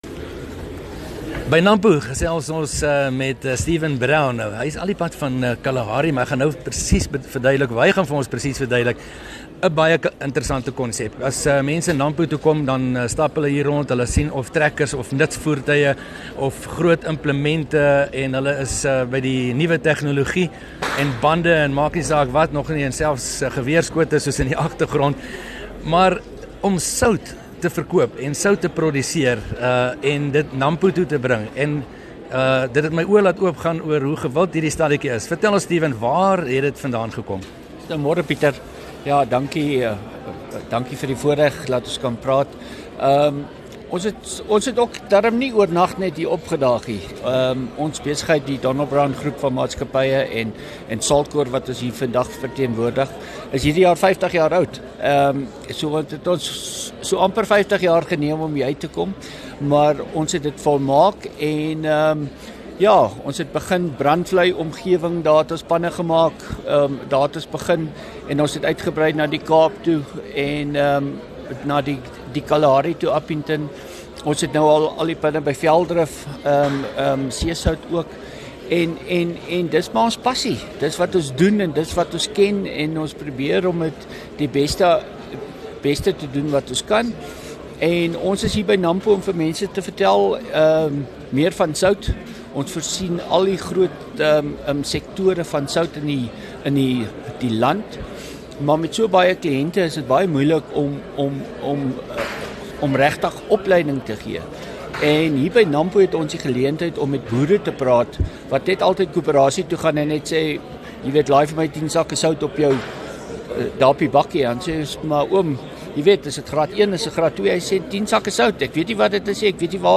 tydens Nampo verlede week gesels met 'n produsent van die Noord-Kaap oor 'n unieke produk wat sy ontstaan in die Kalahari gehad het.